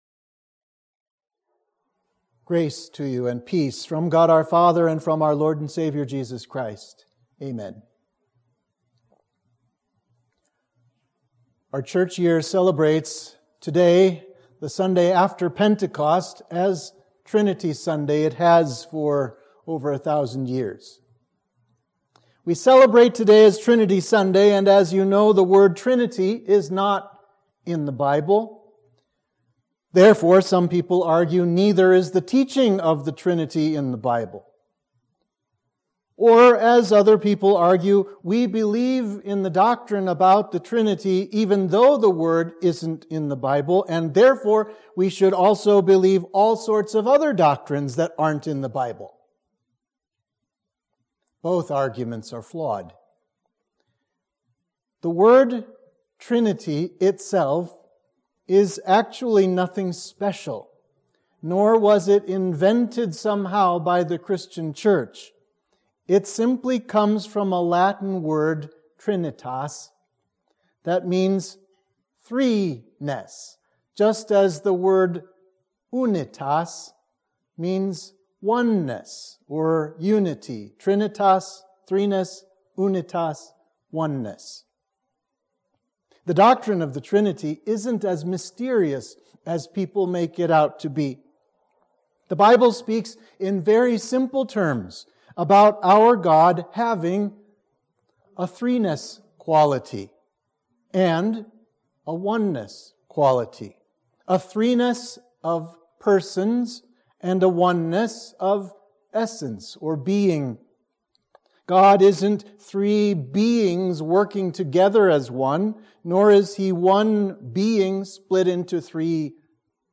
Sermon for Trinity Sunday